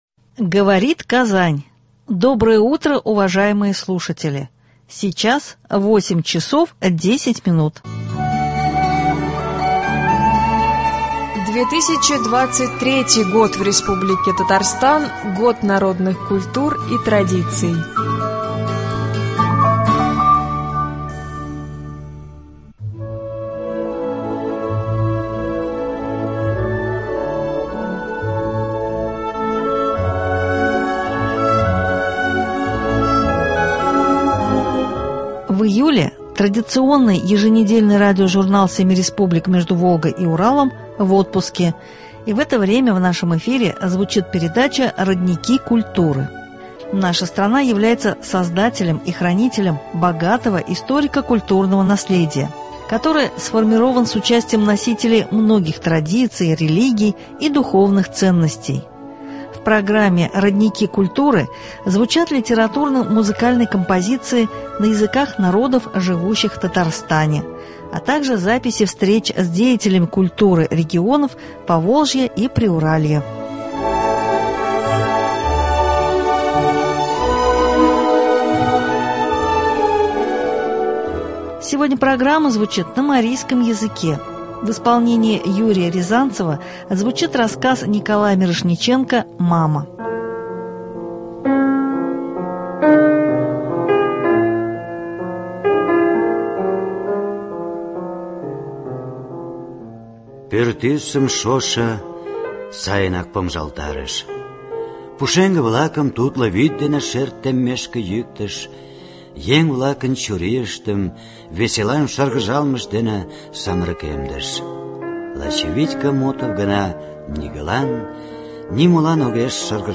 В программе «Родники культуры» звучат литературно — музыкальные композиции на языках народов, живущих в Татарстане, записи встреч с деятелями культуры регионов Поволжья и Приуралья.